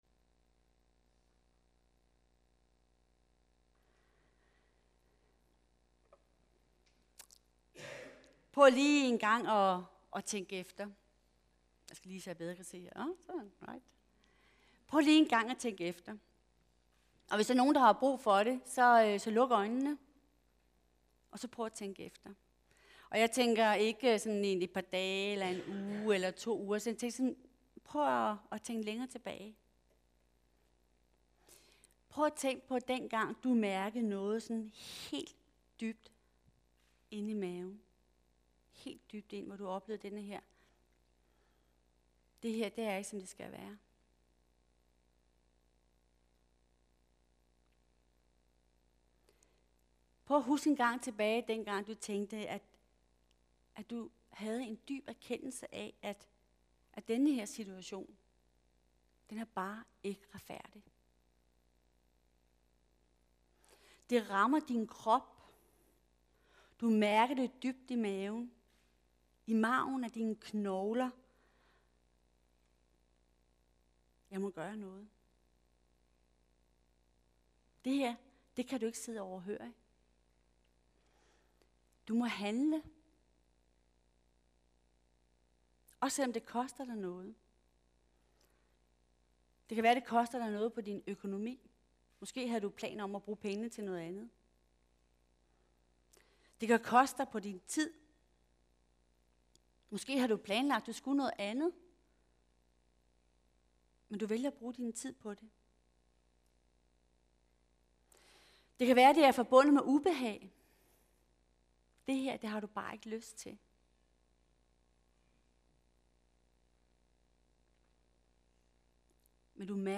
Hør mere i dagens gudstjeneste